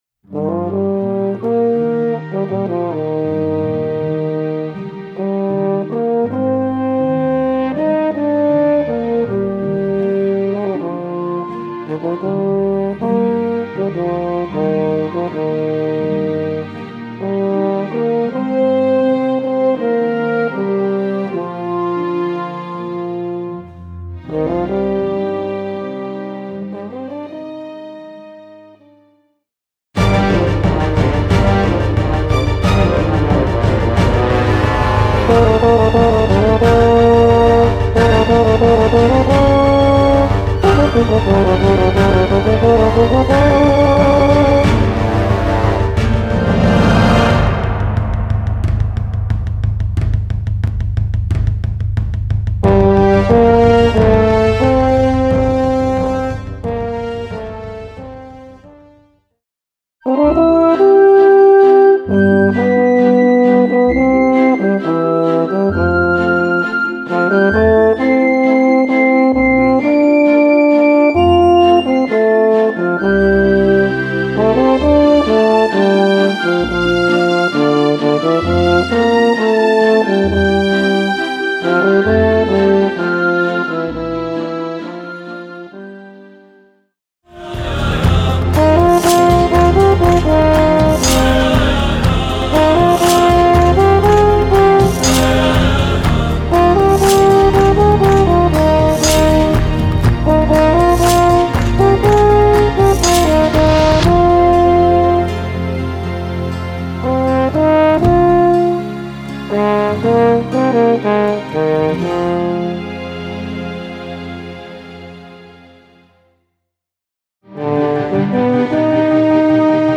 Voicing: French Horn w/ Audio